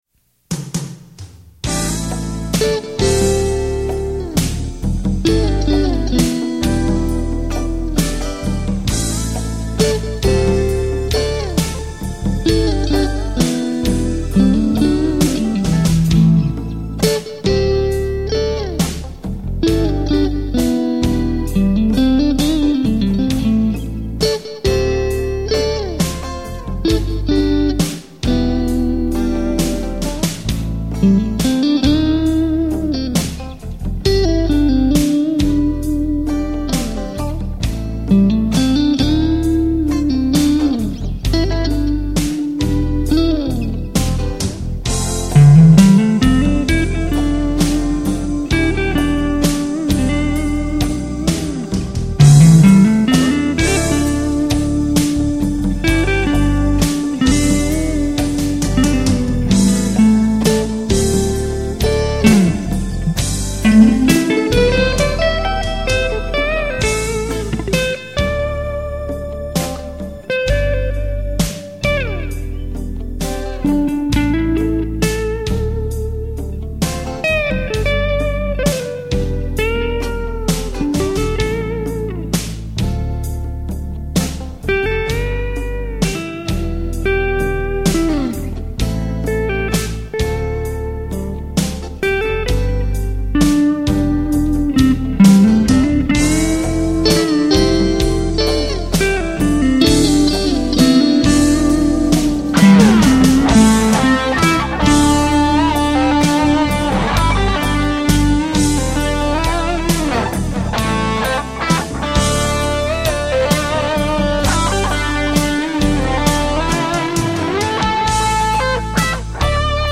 PISTES AUDIO (guitares & basse) + MIDI
SlowHand (Blues-Rock . joué sur ma Strat avec micros artisanaux "Lauzon")